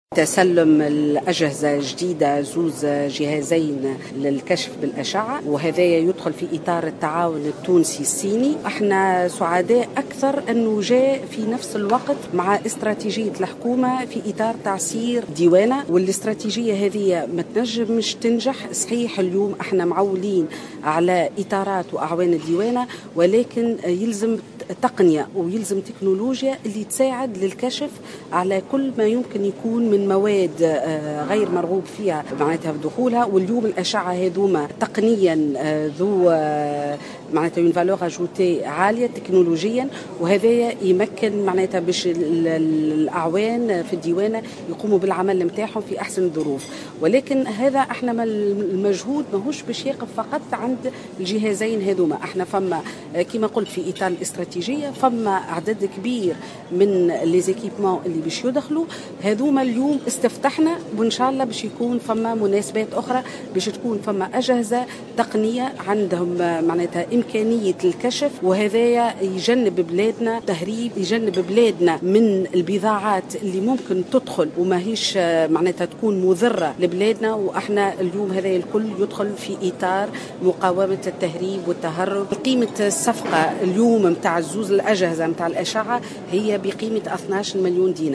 وأكدت كاتبة الدولة لدى وزير التنمية والاستثمار والتعاون الدولي المُكلفة بالتعاون الدولي آمال عزوز في تصريح